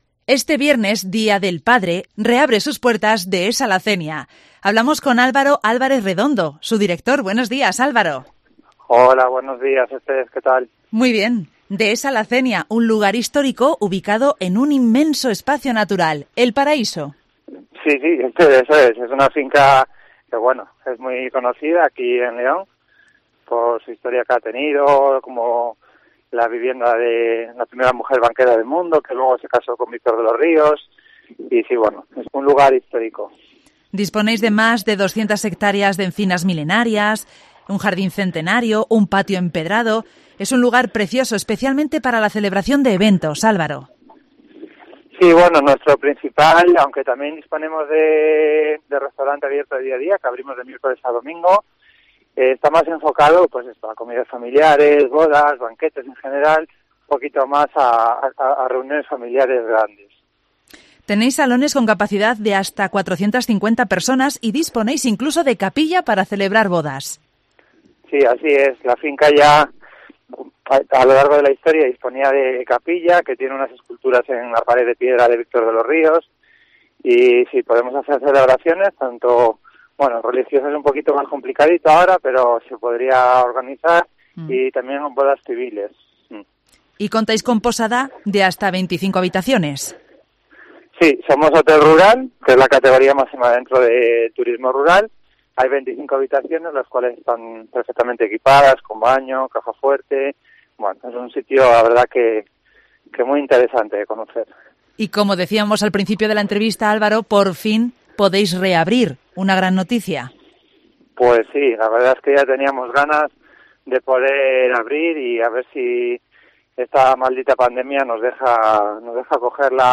entrevista
en el Mediodía COPE en la provincia de León